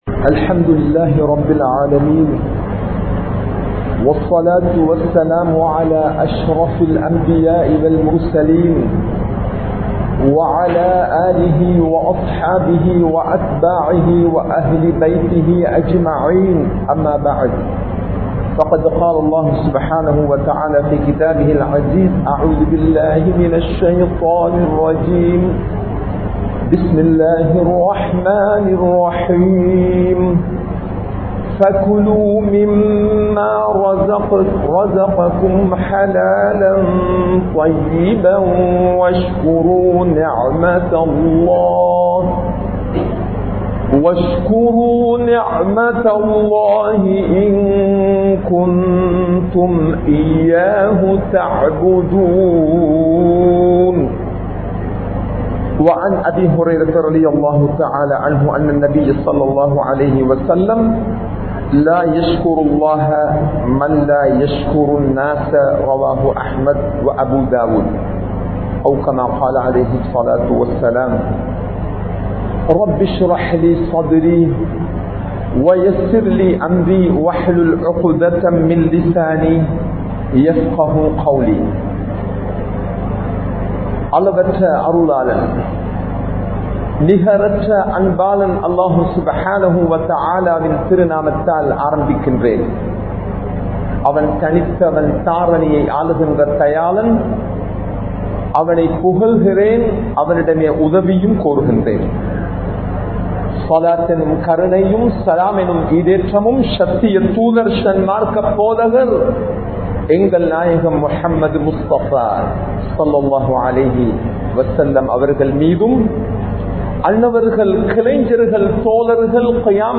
நன்றி செலுத்த மறந்த உறவுகள் | Audio Bayans | All Ceylon Muslim Youth Community | Addalaichenai